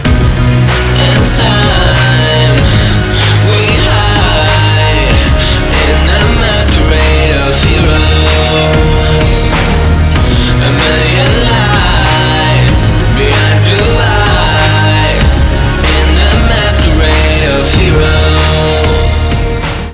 • Качество: 192, Stereo
мужской вокал
мелодичные
спокойные
Pop Rock